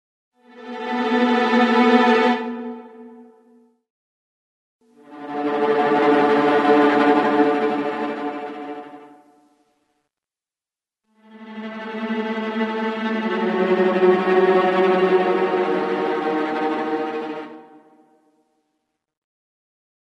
Атмосферные звуки угрозы
Скрипка предупреждает об опасности